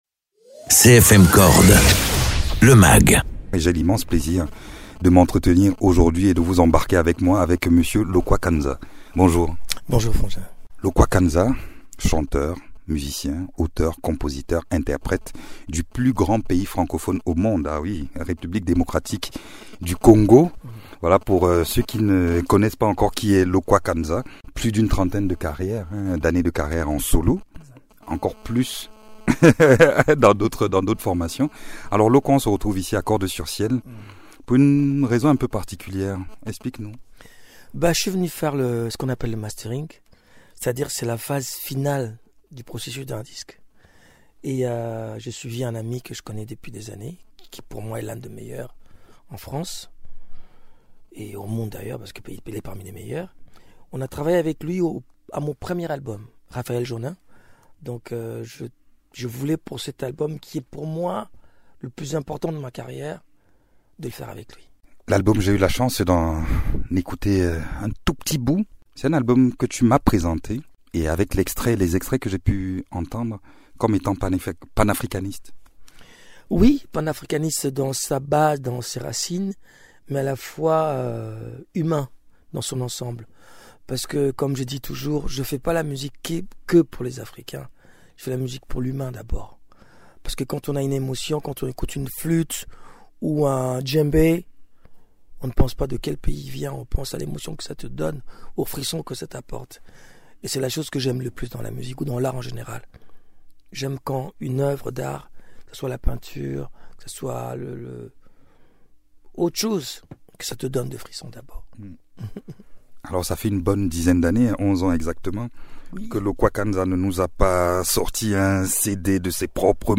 Interviews
Invité(s) : Lokua Kanza, musicien-compositeur et interprète.